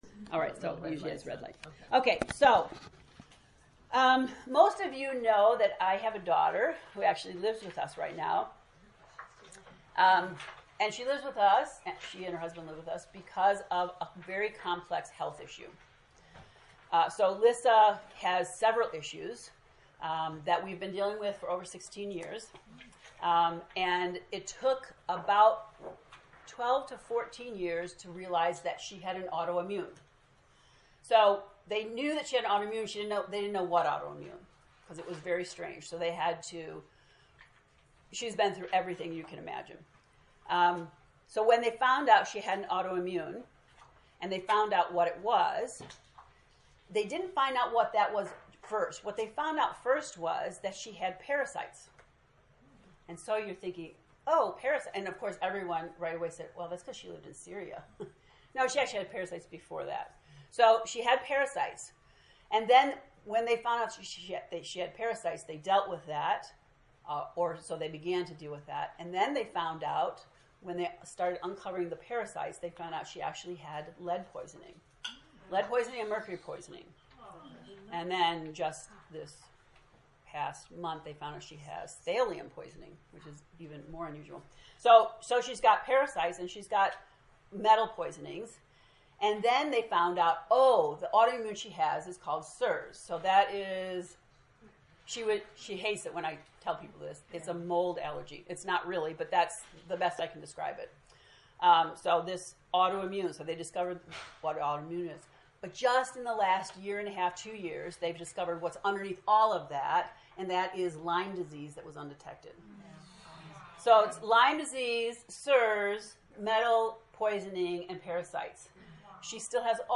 To listen to the lecture 0 Introduction, click below: